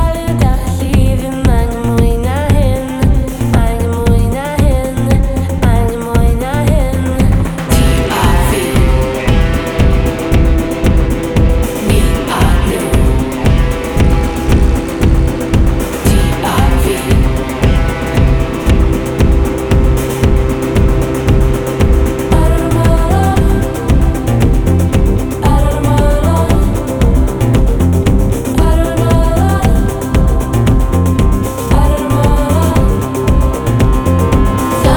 2024-05-01 Жанр: Альтернатива Длительность